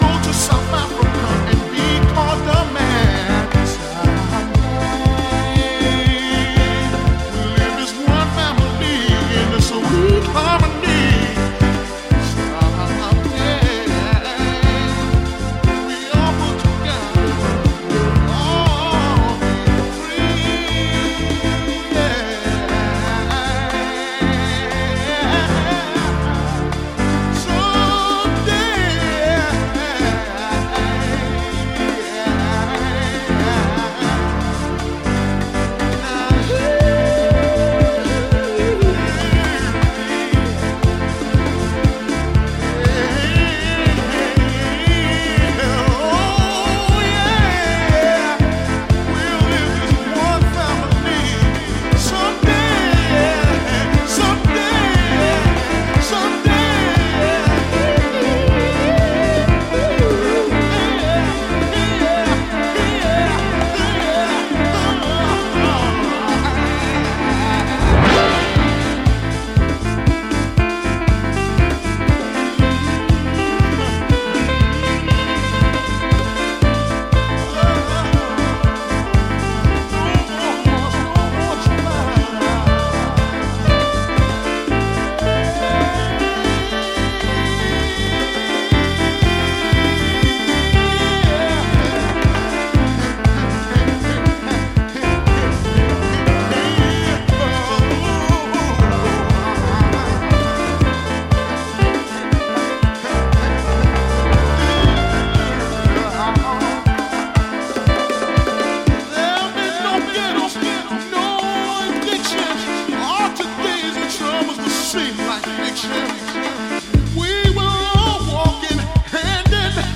ジャンル(スタイル) CLASSIC HOUSE